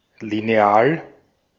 Ääntäminen
Synonyymit principe obligation Ääntäminen France: IPA: [ʁɛɡl] Tuntematon aksentti: IPA: /ʁɛgl/ Haettu sana löytyi näillä lähdekielillä: ranska Käännös Konteksti Ääninäyte Substantiivit 1.